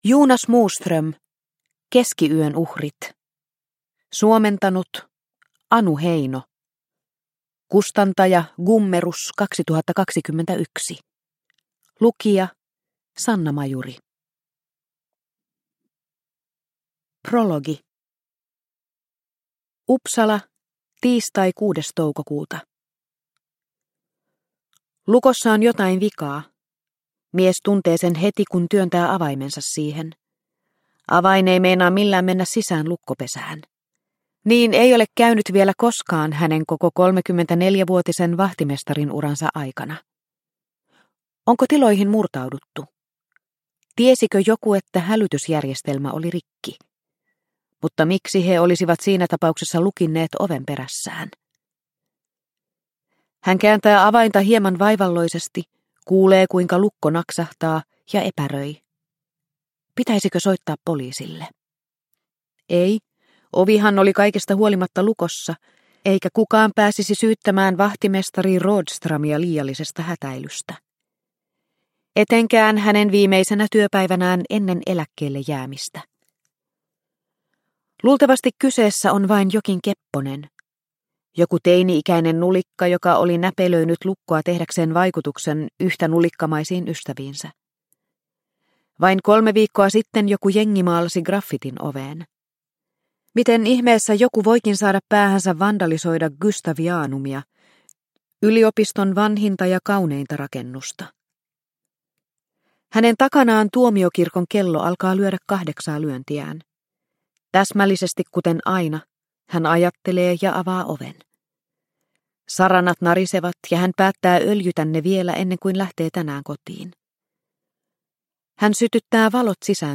Keskiyön uhrit – Ljudbok – Laddas ner